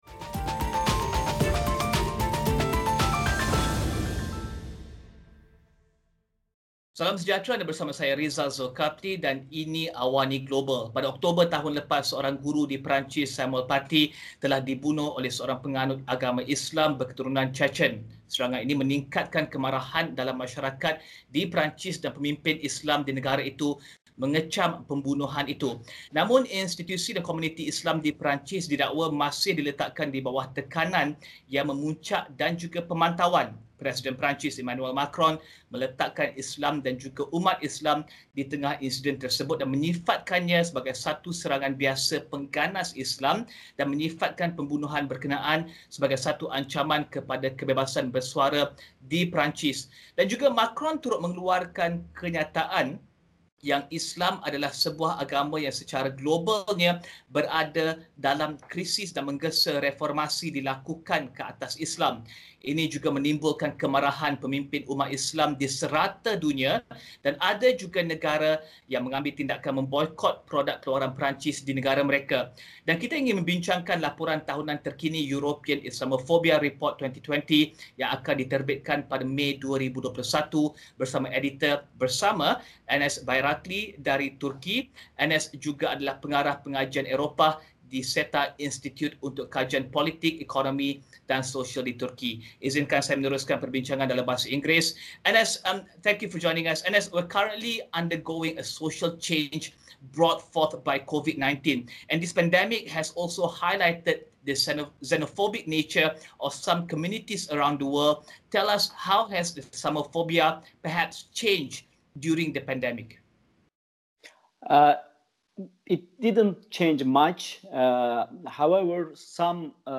menemubual